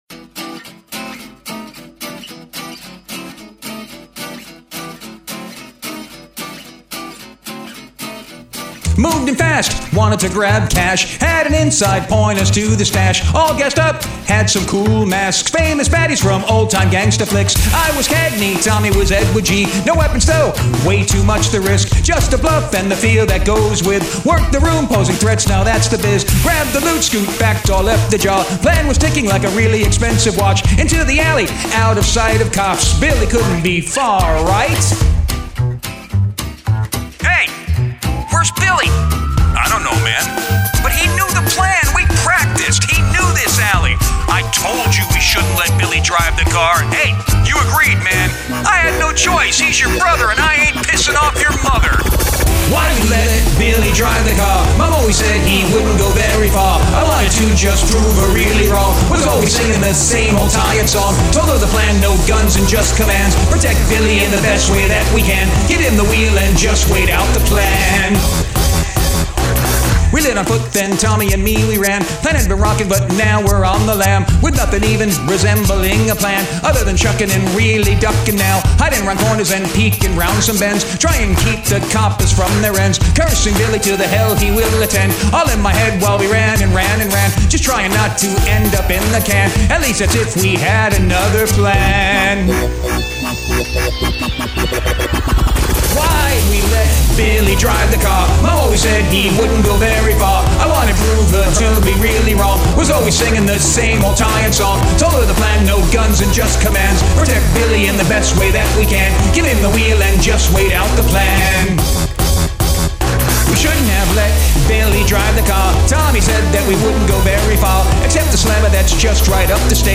From a few years ago, three actually and a personal fave, the first time I took an instrumental from our music production site at work and just went with it.